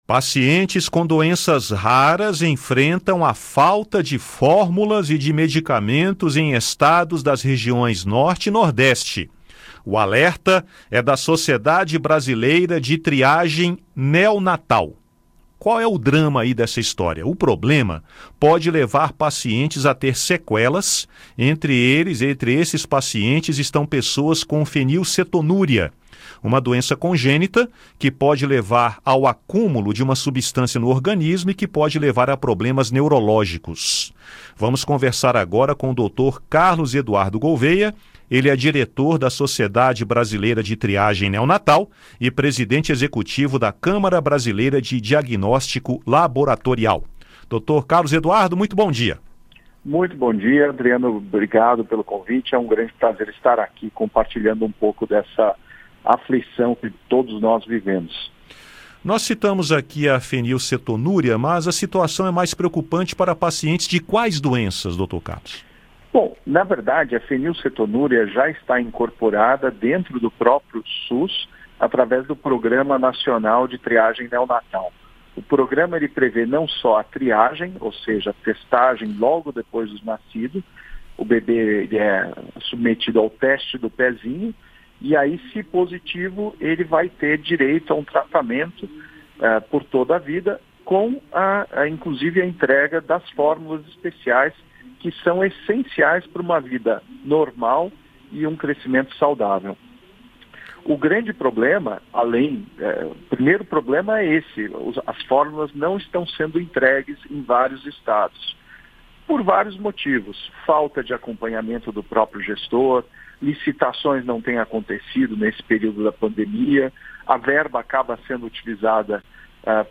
Entrevista: Falta de medicamentos para pessoas com doenças raras